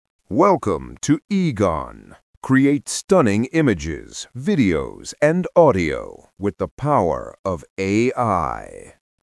キャラクター設定と演技指定による多彩な音声生成：
-v Charon -c "Deep voiced narrator" -d "Cinematic style" -l en |
narration-cinematic.mp3